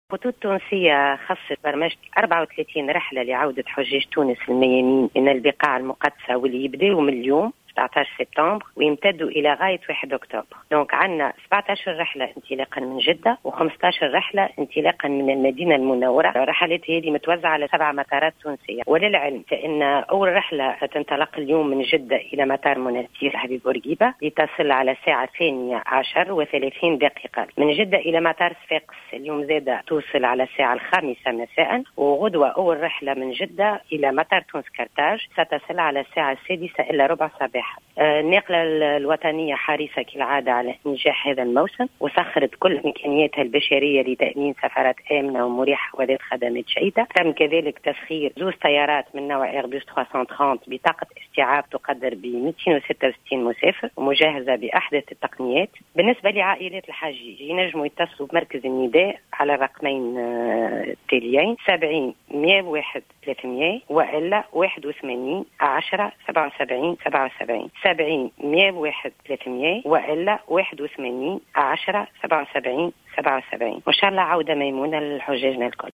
تصريح هاتفي